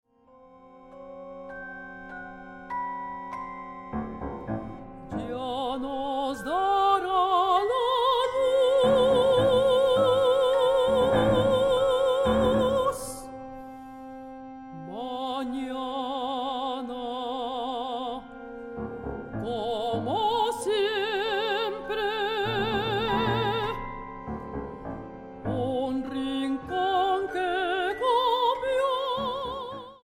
para contralto, piano, armonio y celesta.
mezzosoprano